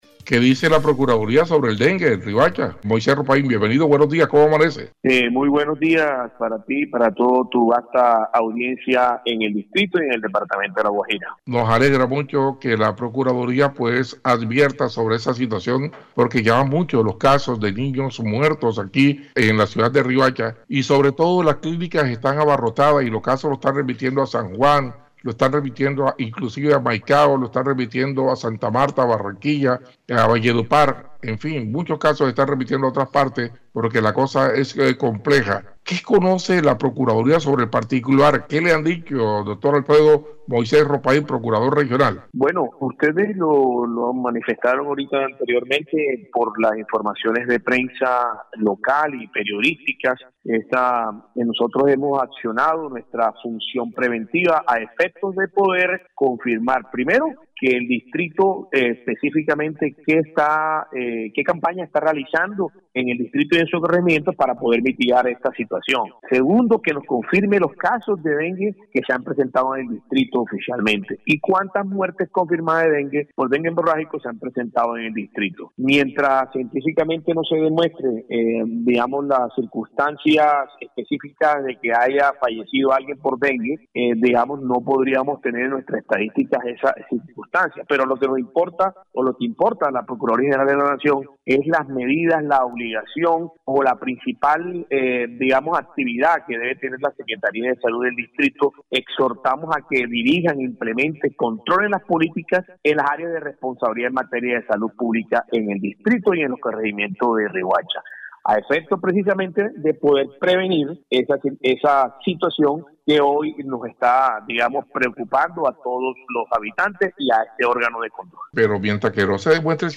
29-DE-SEPTIEMBRE-VOZ-ALFREDO-MOISES-ROPAIN-PROCURADOR.mp3